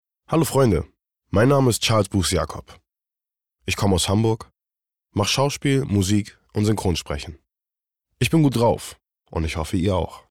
markant, dunkel, sonor, souverän, plakativ
Mittel minus (25-45)